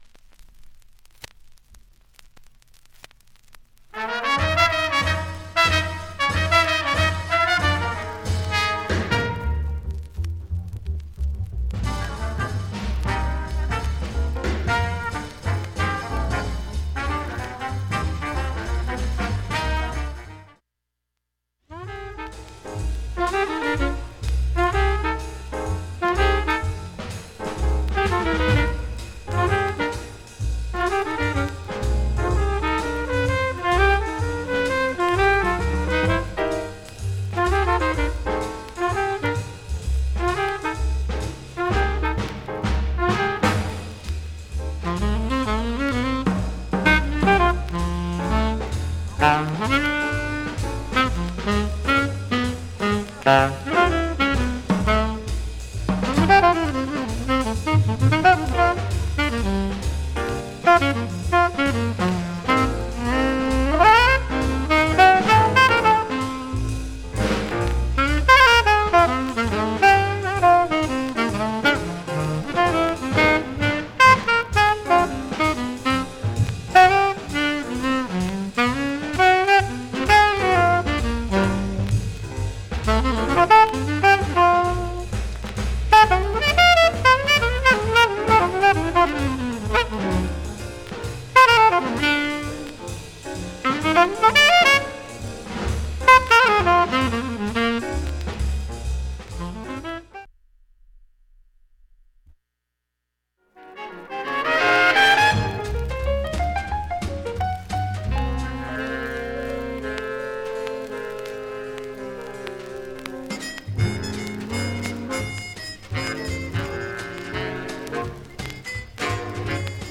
普通に聴けます全曲試聴済み。
B-1から3の30ミリのスレプツは静かな部でかすかに聴こえる
B-3始めにかすかなチリが出ます。
A-1始めにかすかなプツが9回出ます。
周回プツ出ますがかすかなレベルです。（試聴は２分ほど）
現物の試聴（上記録音時間６分）できます。音質目安にどうぞ
Promo White Label, Mono